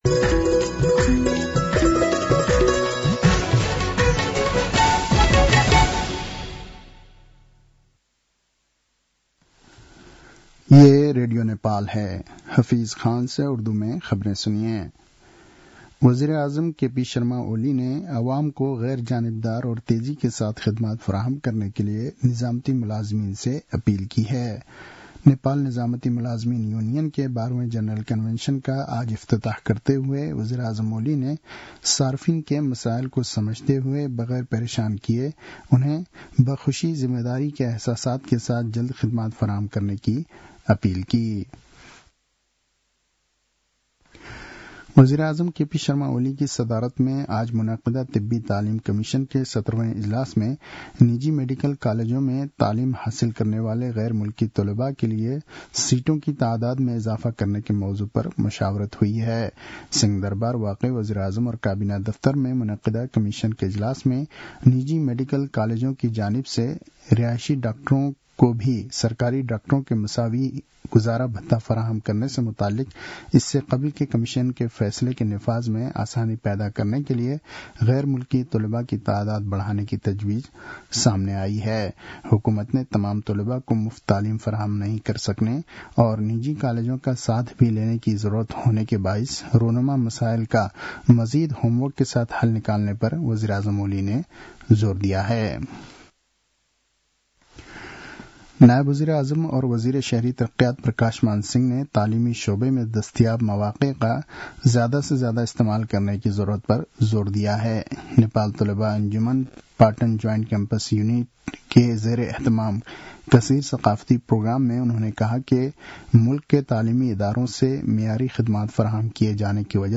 उर्दु भाषामा समाचार : १ फागुन , २०८१